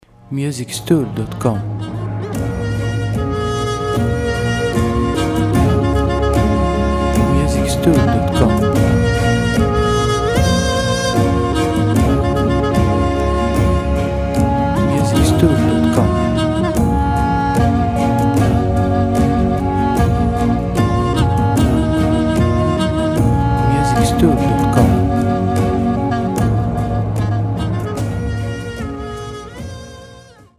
• Type : Instrumental
• Bpm : Adagio
• Genre : Ambient / Cinematic